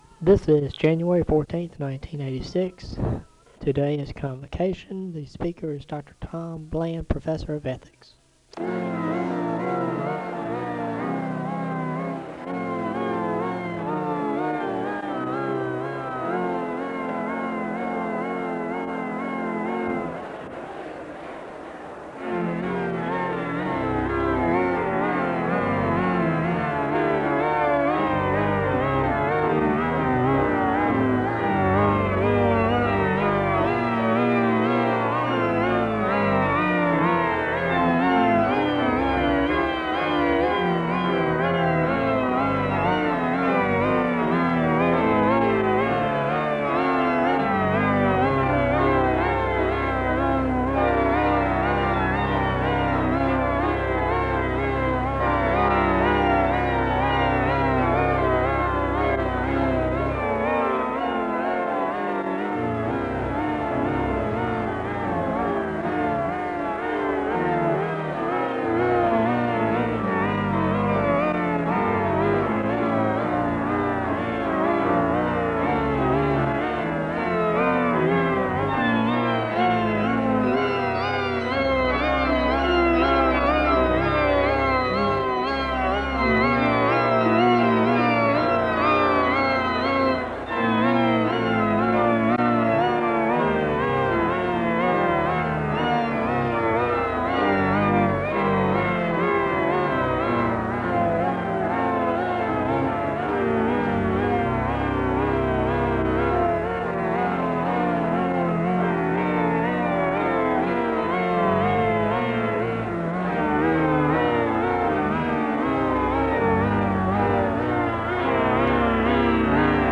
The service begins with organ music (0:00-5:01). There is a moment of prayer (5:02-6:50). A welcome is given to the new students and professor (6:51-8:28).
The service closes in a moment of prayer (34:41).